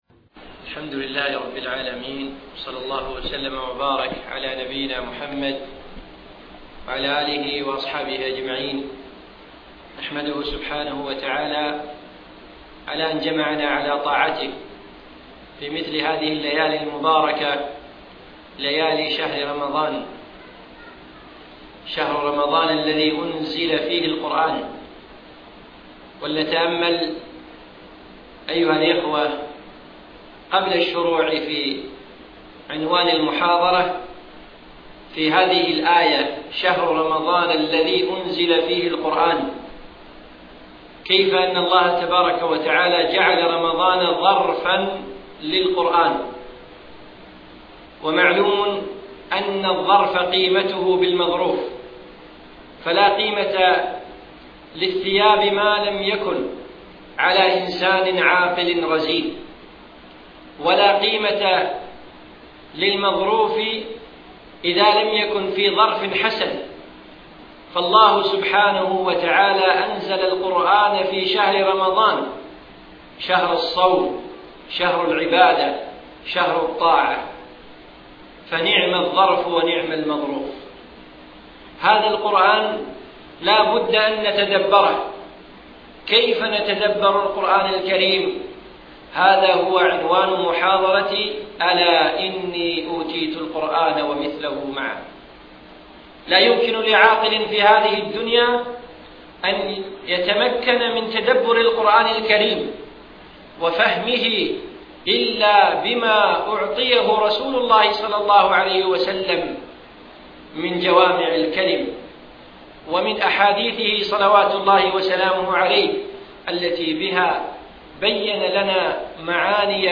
أقيمت المحاضرة في دولة الإمارات